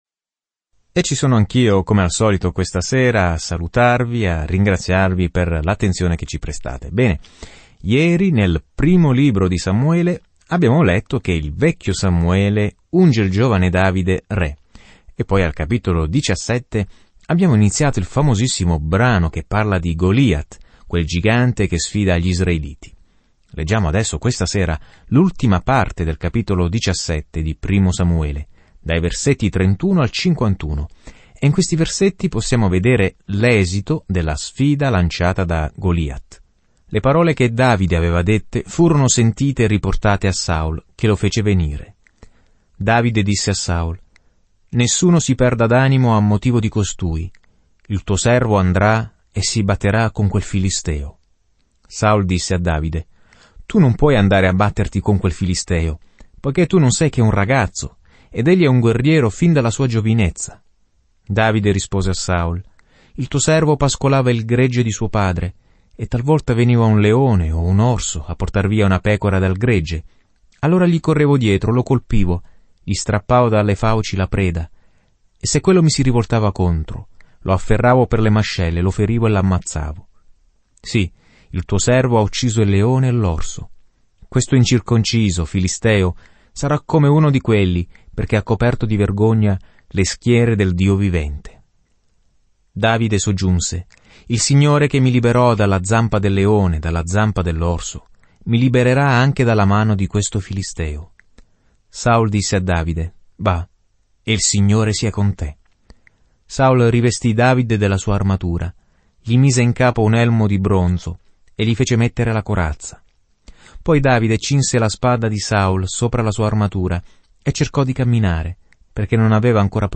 Viaggia ogni giorno attraverso Primo Samuele mentre ascolti lo studio audio e leggi versetti selezionati della parola di Dio.